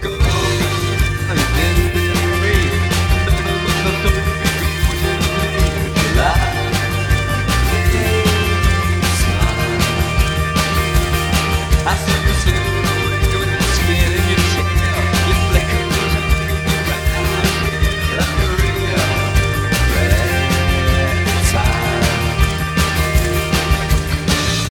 I truly wished this would’ve turned out great as no official instrumental exists, but I can’t exactly fault the algorithms for not being able to track the vocals all that well, as this track has a lot of guitars and other stuff going on.